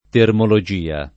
[ termolo J& a ]